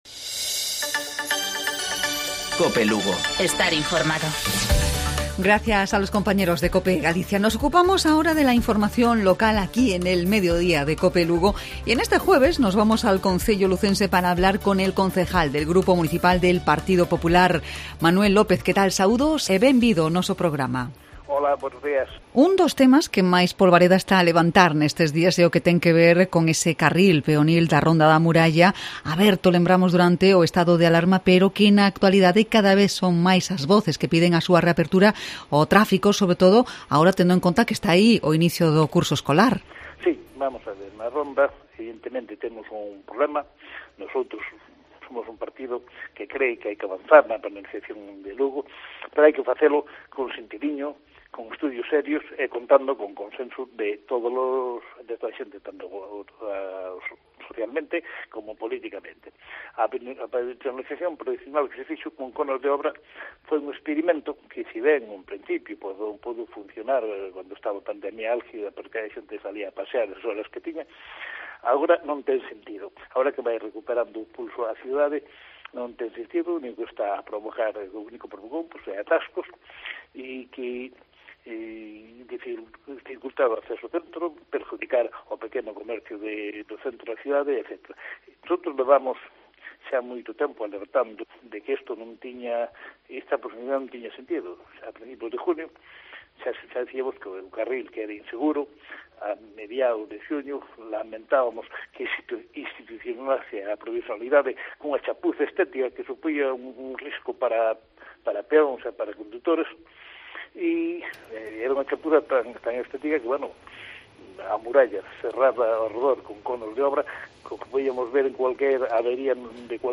AUDIO: En el Mediodía de Cope Lugo charlamos con Manuel López, concejal del GMPP de Lugo